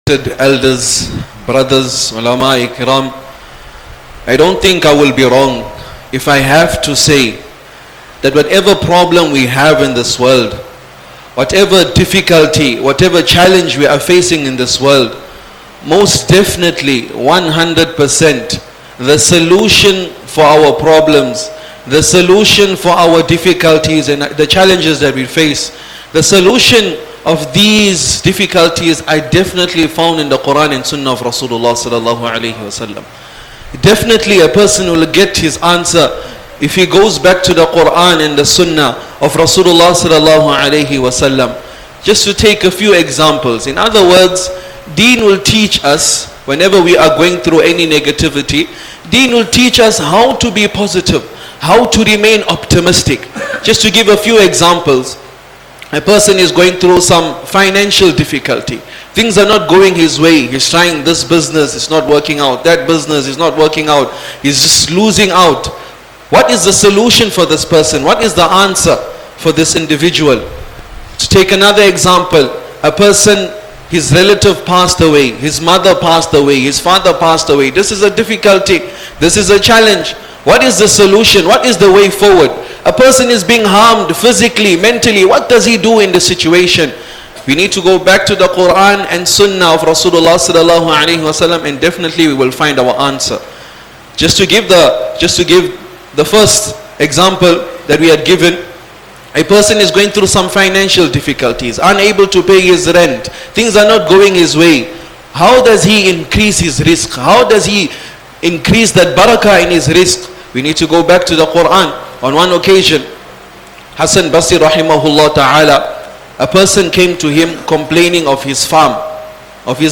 Easily listen to Islamic Lecture Collection